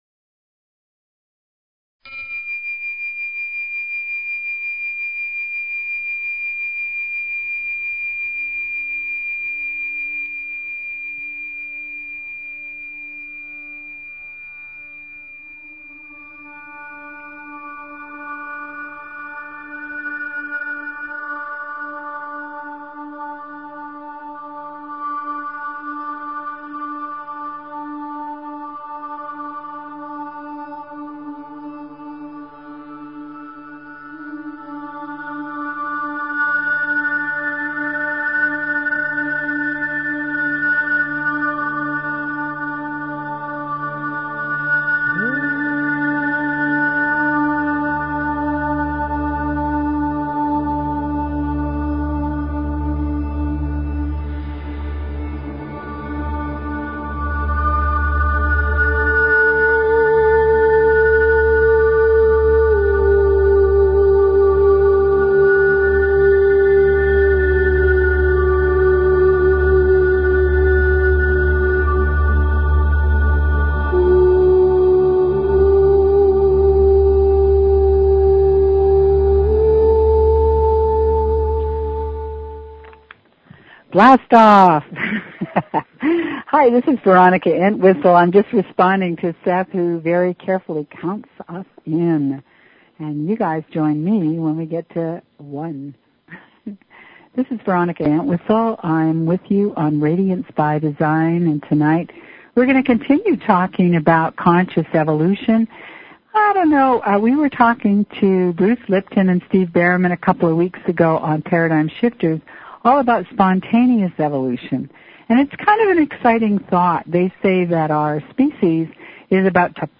Talk Show Episode, Audio Podcast, Radiance_by_Design and Courtesy of BBS Radio on , show guests , about , categorized as
Radiance is a call in show so call in about your life, your questions, the trickery that you find in your daily routine.